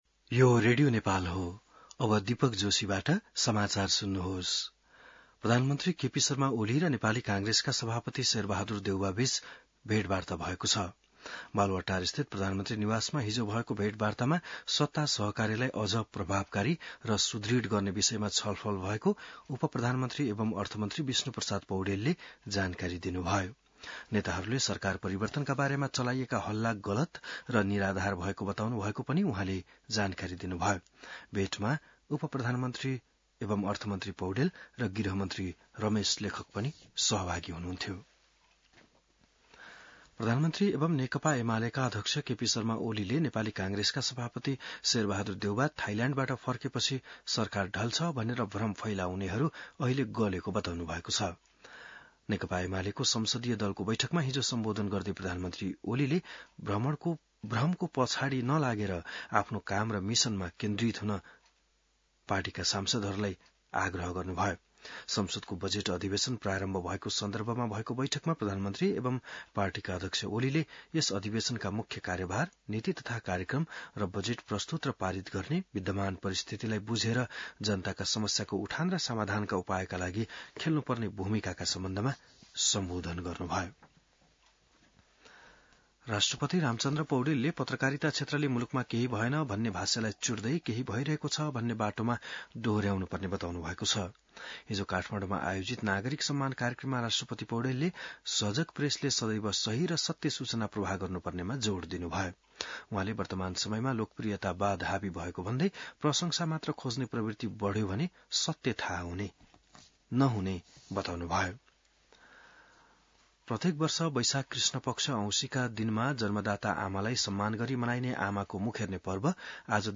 बिहान १० बजेको नेपाली समाचार : १४ वैशाख , २०८२